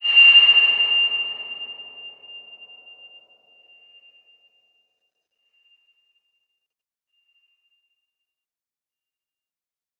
X_BasicBells-F5-pp.wav